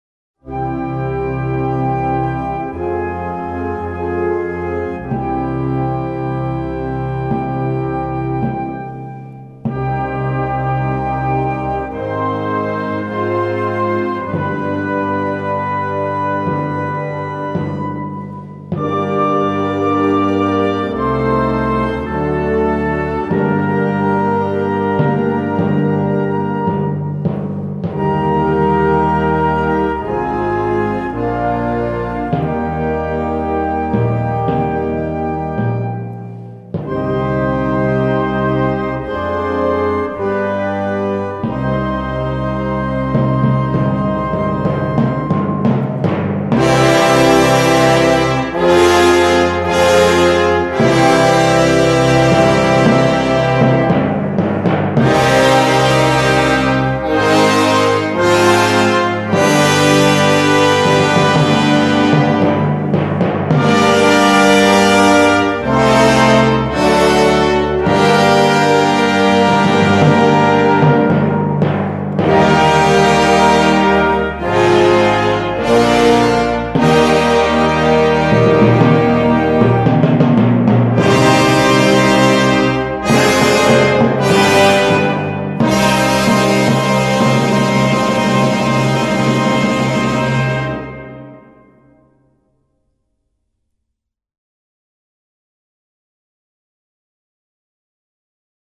Gattung: Kirchenmusik
Besetzung: Blasorchester